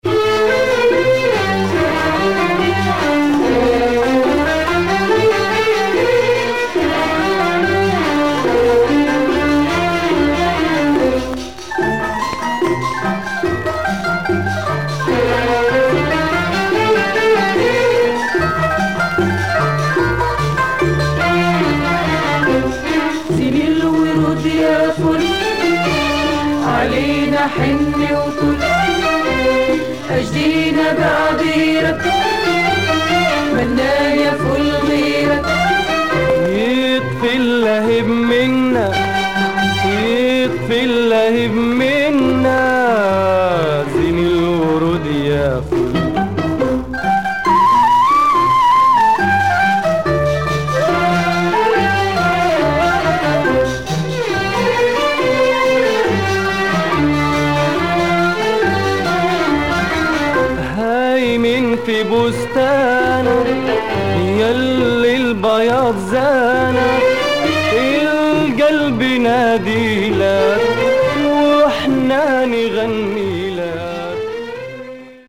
No marks, just a little dust.